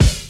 Index of /90_sSampleCDs/Best Service Dance Mega Drums/BD HIP 02 B